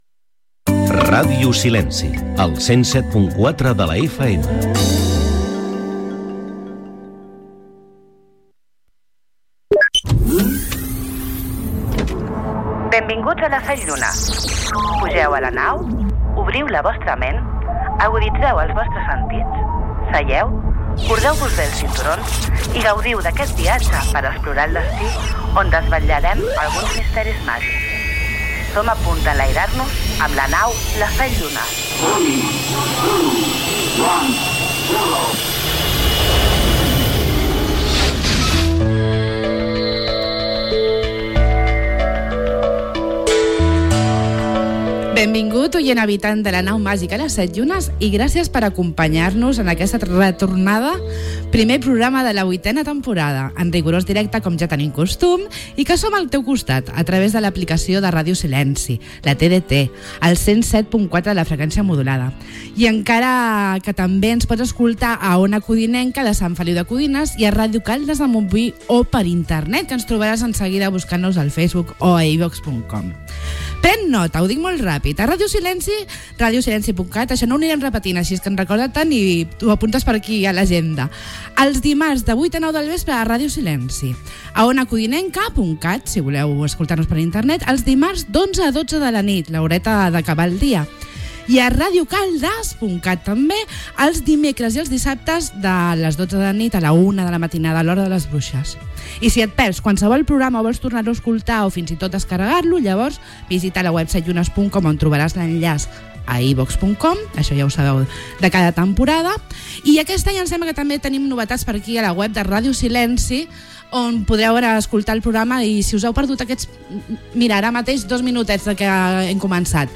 fb4600d7dd53dde92ffe046f88ea9dc714527d7f.mp3 Títol Ràdio Silenci Emissora Ràdio Silenci Titularitat Pública municipal Nom programa Les 7 llunes màgiques Descripció Indicatiu de la ràdio, careta, presentació del primer programa de la vuitena temporada.
Invitats del programa i conversa telefònica amb el primer sobre assumptes esotètics